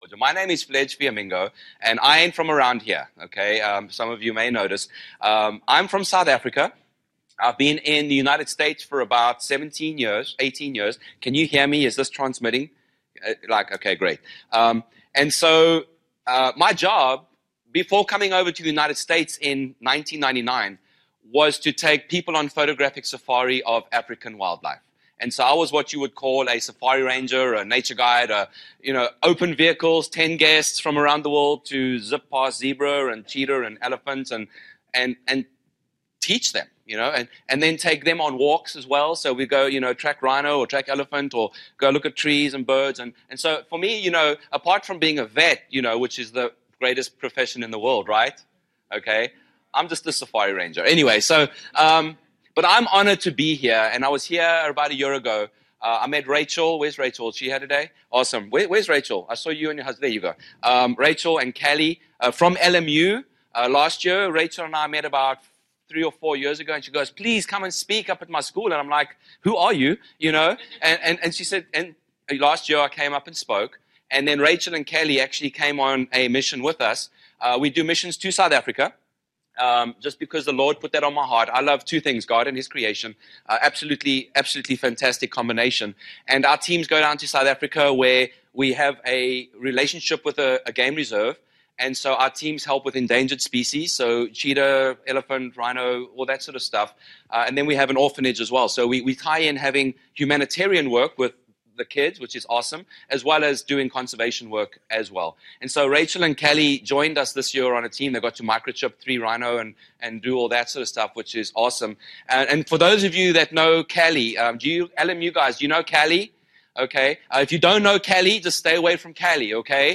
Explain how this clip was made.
"Recorded Live" - Tracking the Lion of Judah events "Real Life. Real Impact" Christian Veterinary Mission Annual Conference - Lincoln Memorial University, TN.